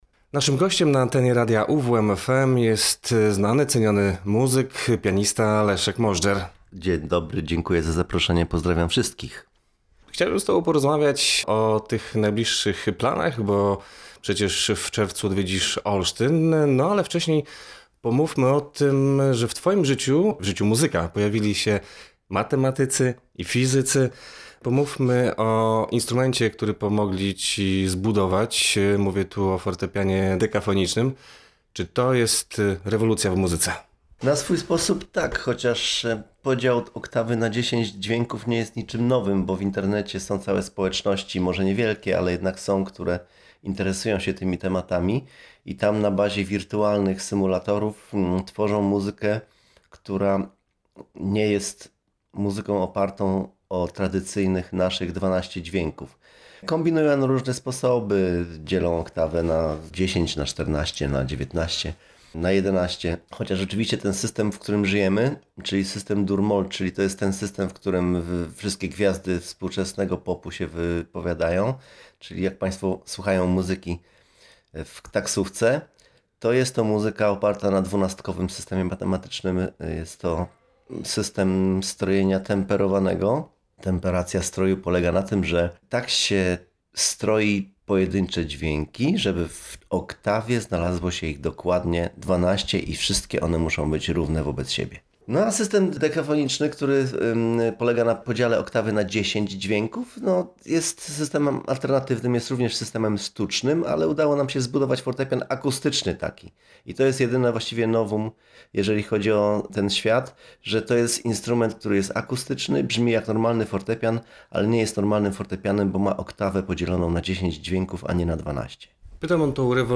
spotkał się z artystą podczas jego wizyty w salonie